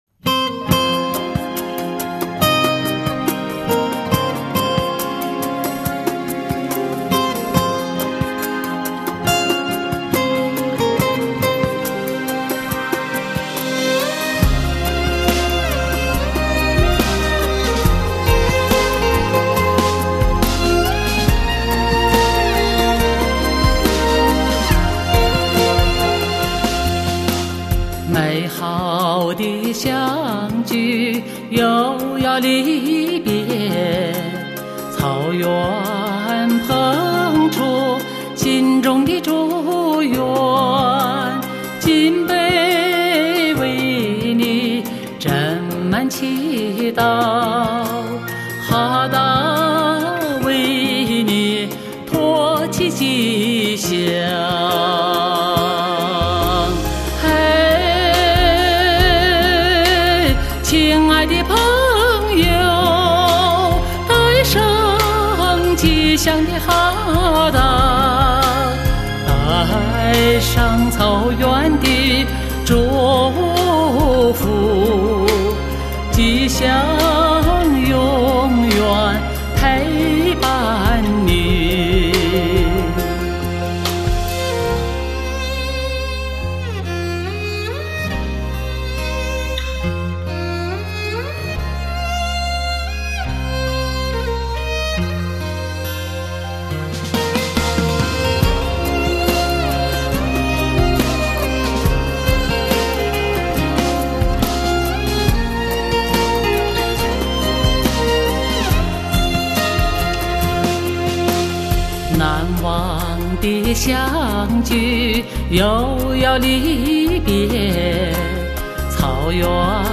歌声中流淌出大草原的诗意柔情
推出发烧级全新个人专辑